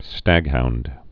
(stăghound)